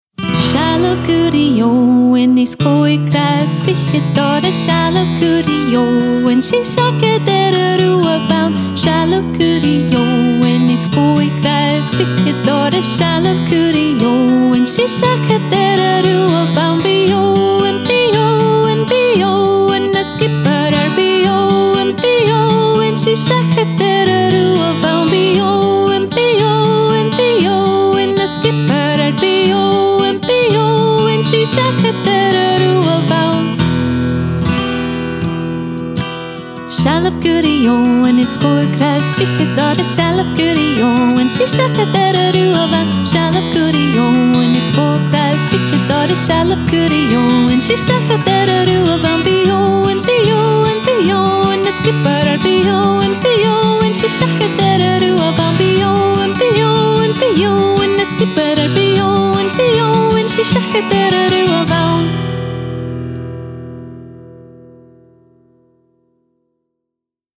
Gaelic Music Download Seallaibh curraigh Eòghainn MP3